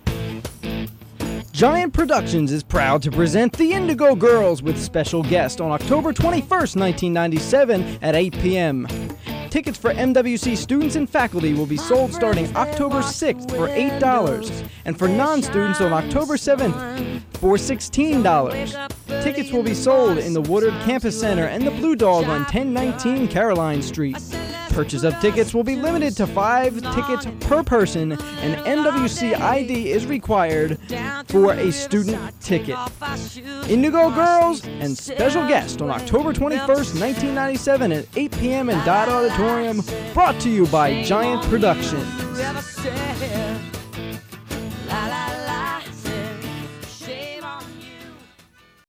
lifeblood: bootlegs: 1997-10-21: wmwc at university of mary washington - fredricksburg, virginia
02. advertisement for the show #01 (0:48)